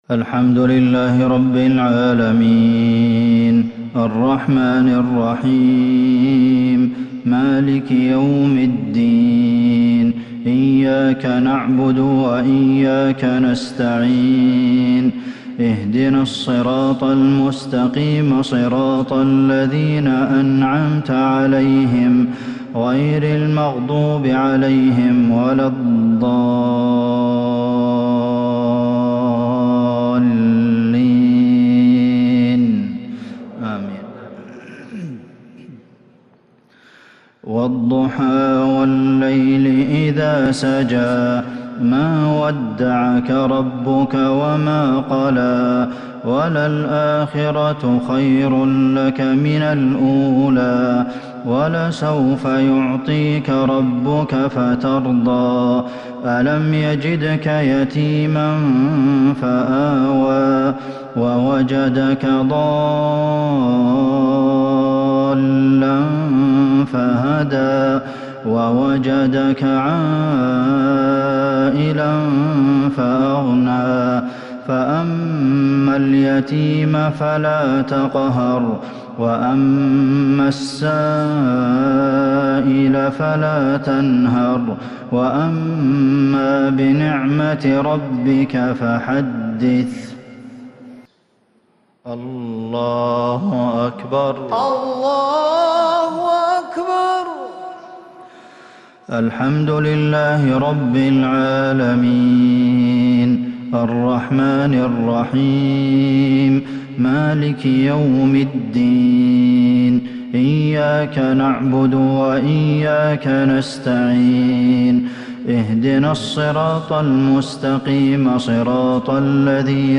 مغرب الثلاثاء 9-4-1442هـ سورتي الضحى و المسد | Maghrib prayer Surah adh-Duha and Al-Masad 24/11/2020 > 1442 🕌 > الفروض - تلاوات الحرمين